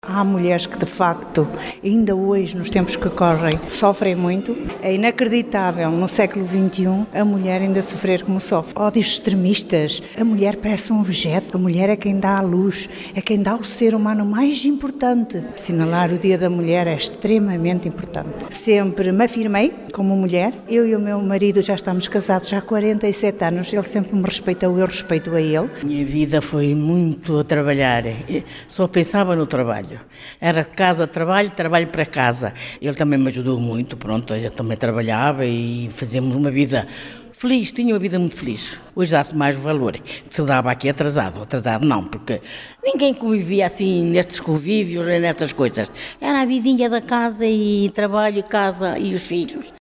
No decorrer da manhã, as participantes partilharam histórias de vida, reforçando a importância de se assinalar este dia:
vox-pop-univ-senior.mp3